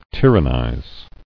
[tyr·an·nize]